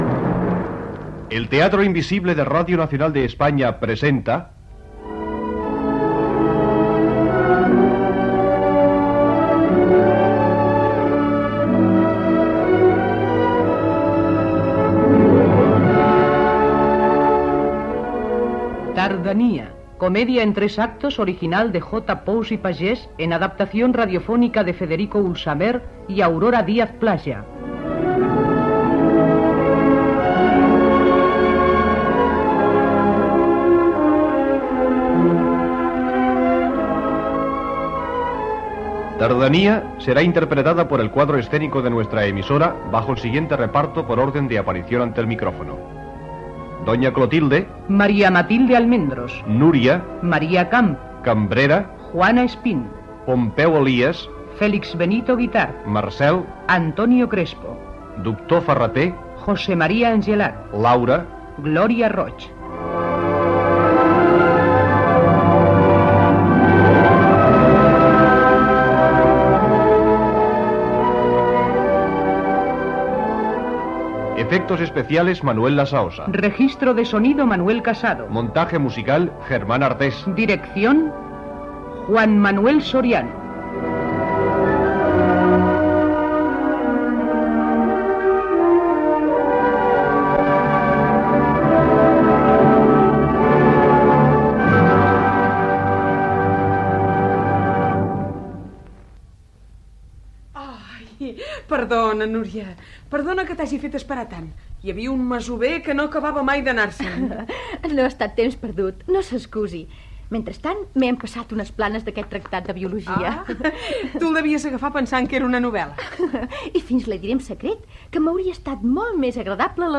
Versió radiofònica
Careta del programa amb l'equip i repartiment i primeres escenes de l'obra Gènere radiofònic Ficció